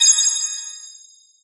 coin_hit_01.ogg